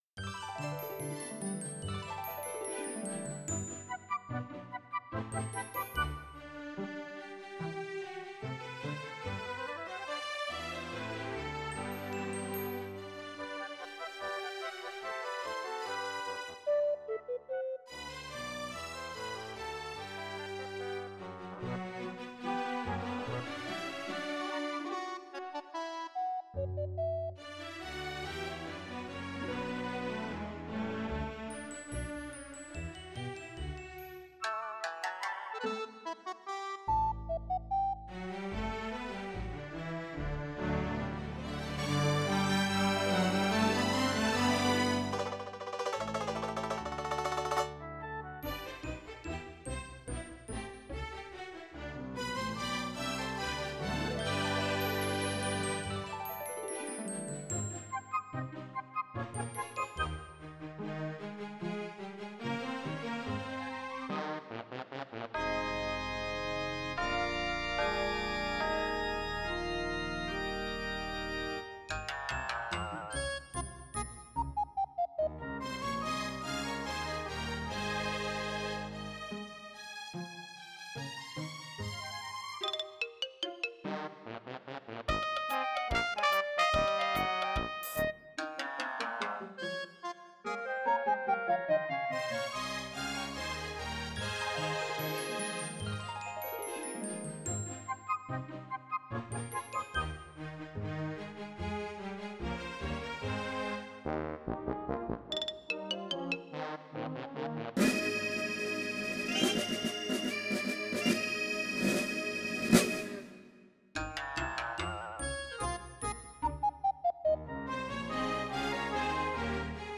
Time to pull out this silly little Christmas MIDI again.
A collection of Christmas MIDI files sequenced for the Roland SC-55 has been floating around since at least 1993, and the author of these files is unknown.